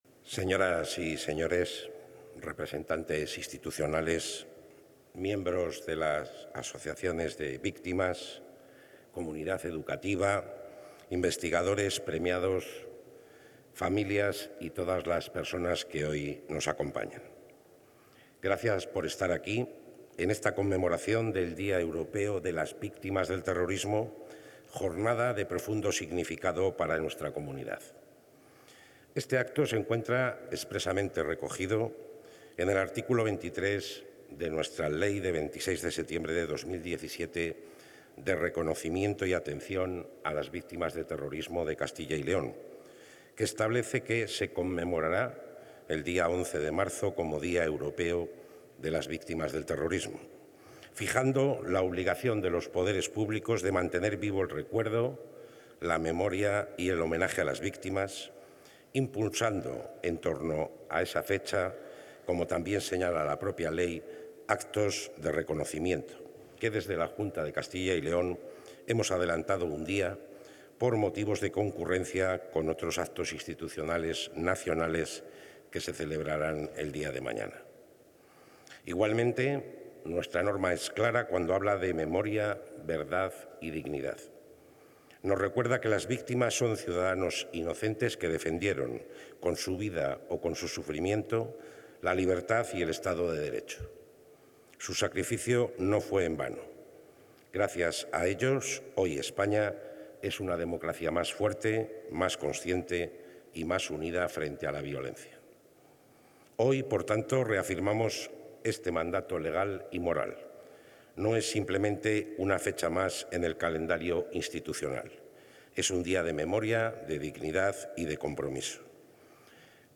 La Junta de Castilla y León ha celebrado hoy el acto institucional con motivo del Día Europeo de las Víctimas del Terrorismo, una jornada dedicada al recuerdo, al reconocimiento y al homenaje a todas las personas que han sufrido la violencia terrorista. El acto ha estado presidido por el consejero de la Presidencia, Luis Miguel González Gago, y ha contado con la participación de representantes institucionales, asociaciones de víctimas, miembros de la comunidad educativa y universitaria, así como familiares y ciudadanos.